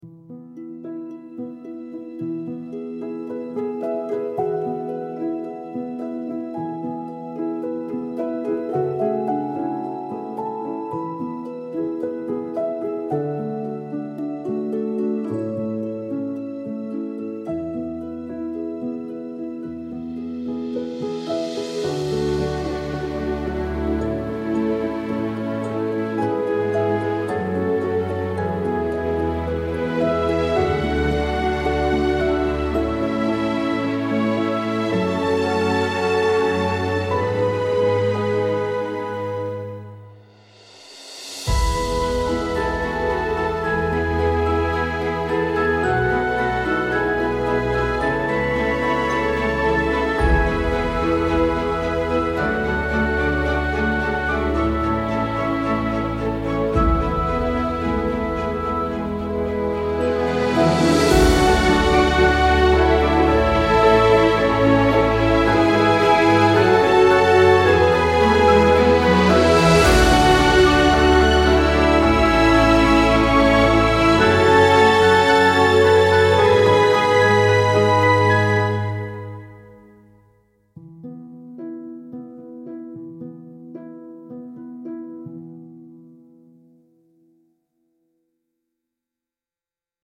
refined cinematic orchestral piece with golden warmth and graceful strings